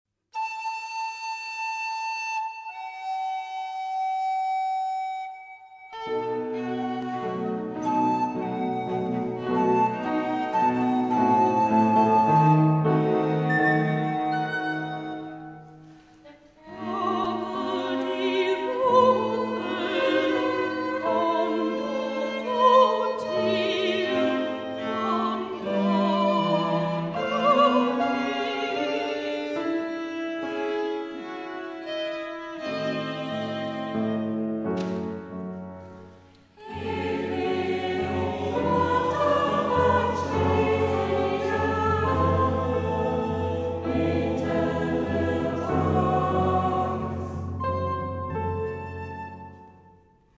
und der ev. Kirchenchor Offenburg-Weier.
Besetzung: Sopran-Solo, gemischter Chor, Kammerorchester